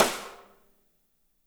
-MEDSNR2W -R.wav